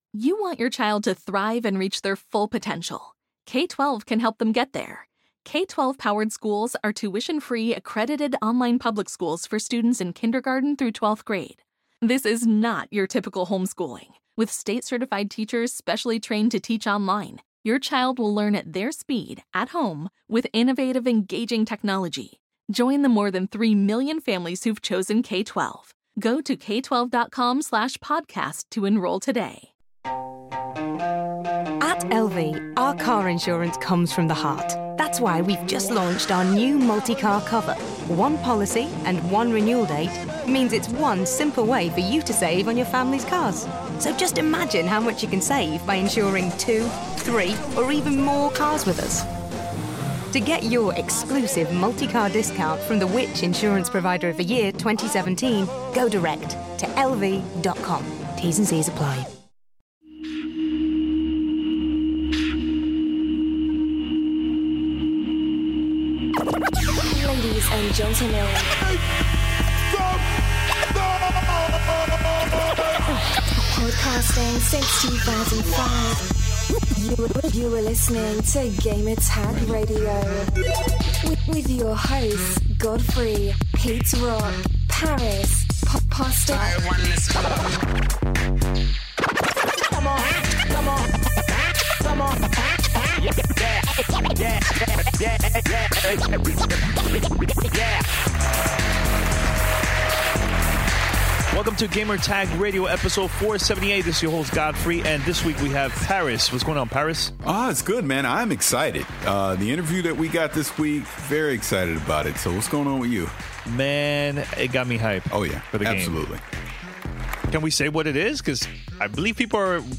Episode #478 - The Witcher 3: Wild Hunt Interview with CD Projekt RED
This week on episode 478 of Gamertag Radio, we sit down with CD Projekt Red to discuss The Witcher 3: Wild Hunt. During our conversation we go over introducing new players to the Witcher franchise, how your choices will matter and affect the story, the importance of potions and crafting, along with changes to the combat system. Towards the end of the interview, we discuss DLC and the influences of The Legend of Zelda and Red Dead Redemption.